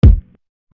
LIMP WRIST KICK.wav